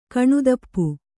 ♪ kaṇudappu